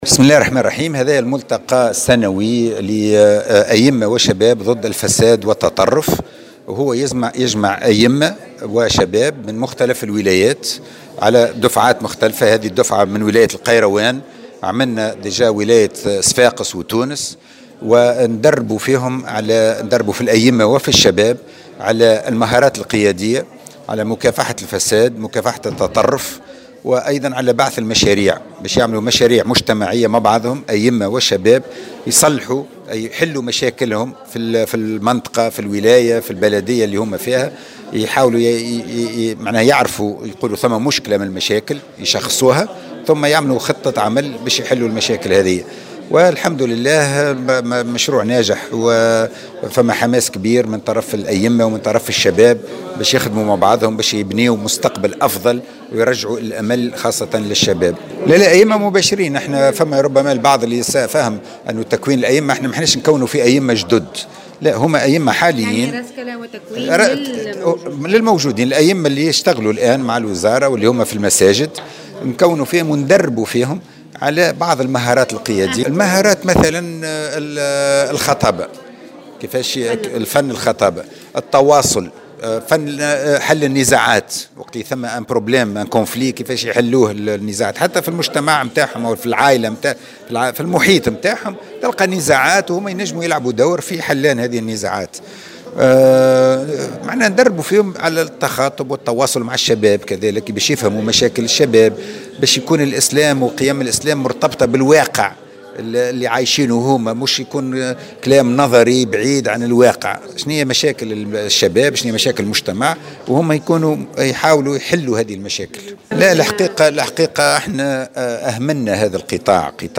على هامش الملتقى السنوي أئمة شباب ضد التطرف والفساد المنعقد مساء أمس بالحمامات تكوين 800 امام الى حدود السنة المقبلة وذلك لمزيد ترسيخ قيم التسامح وترسيخ الاخلاق السمحة